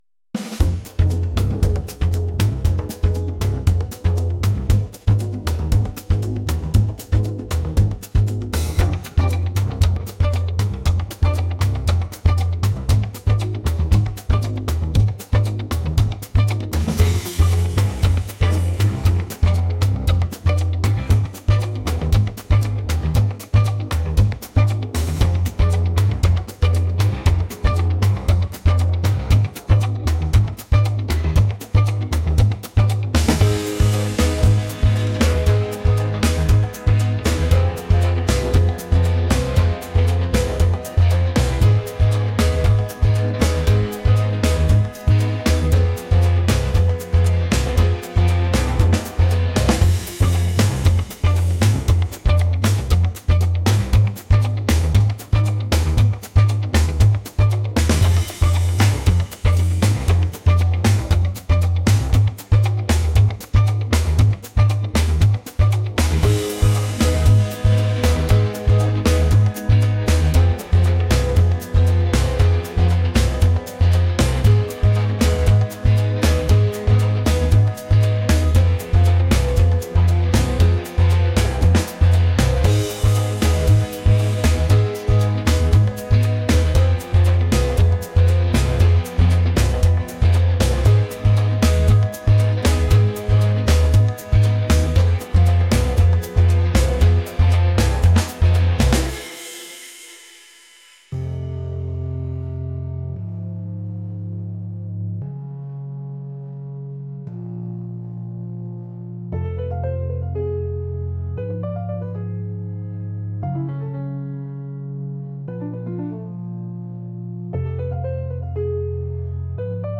latin | lounge | groovy